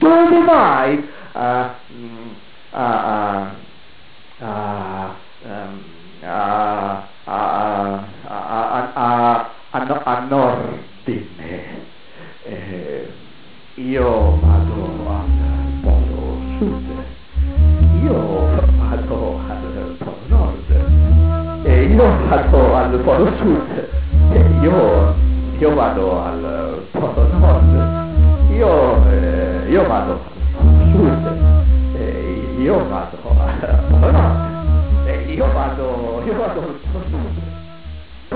contrabbasso
flauto
un tentativo di introdurre il contrasto in poesia sonora